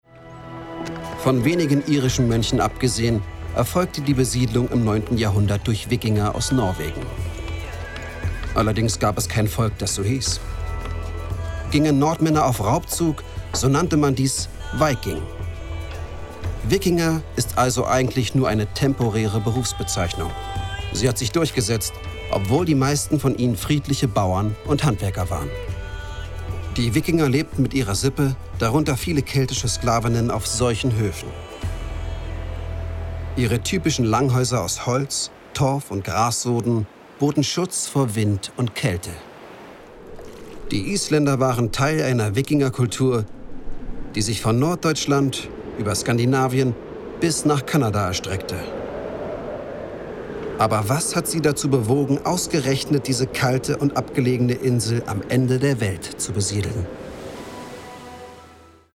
Stimmproben